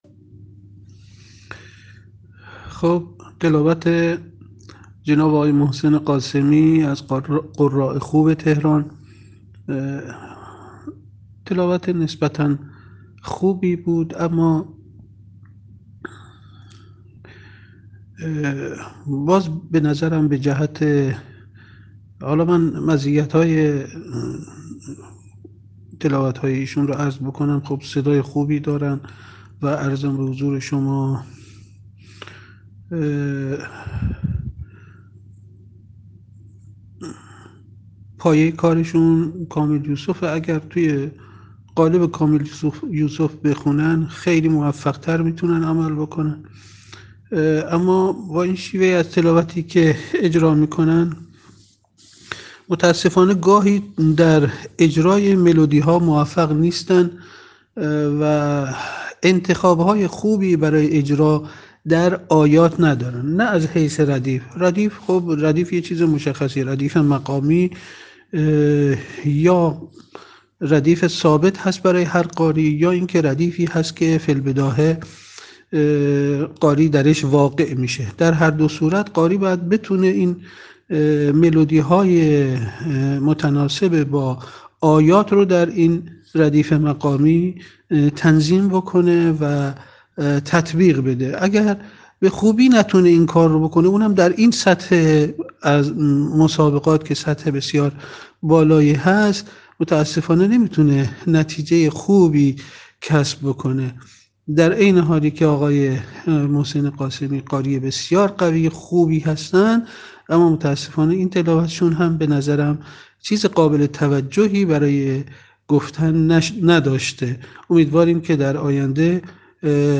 تحلیل تلاوت